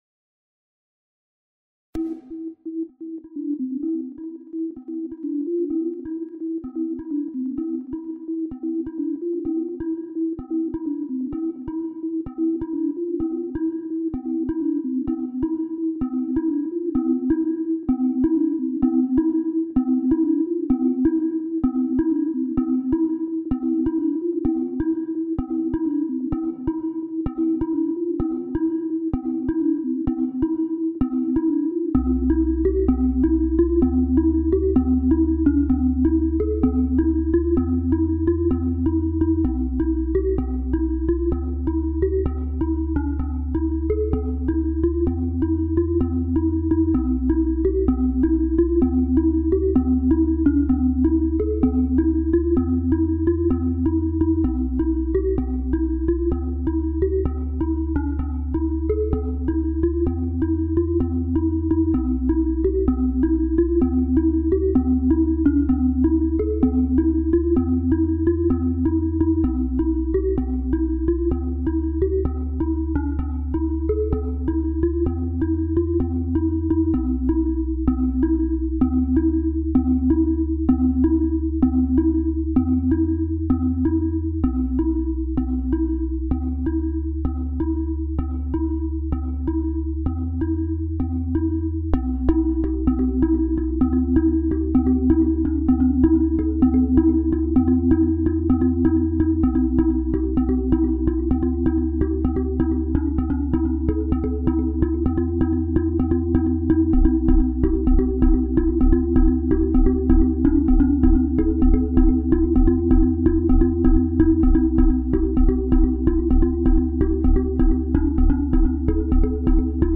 Green Lane [Ambient Mix] (Mp3)
Then magically somehow it opened for about 25 minutes, i was able to cut the drums and shorten what i had into a small ambient track. The levels are still way off, but i'm very happy i saved whats left of it.